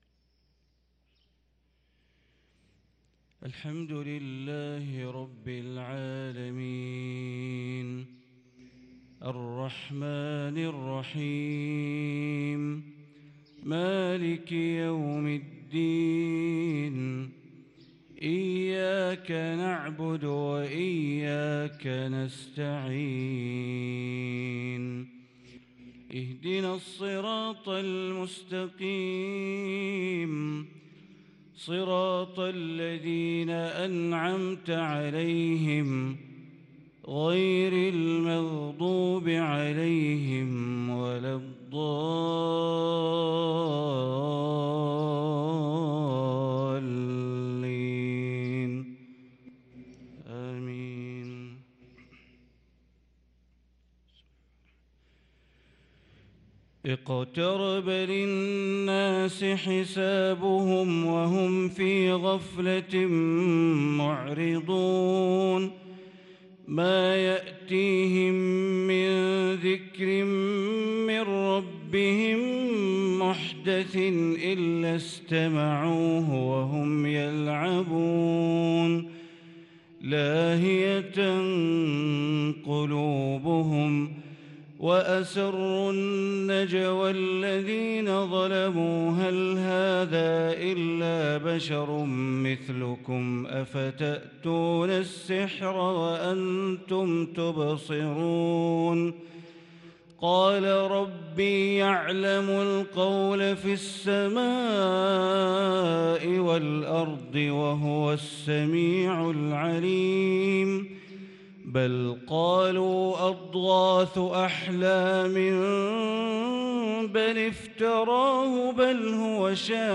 صلاة الفجر للقارئ بندر بليلة 9 صفر 1444 هـ
تِلَاوَات الْحَرَمَيْن .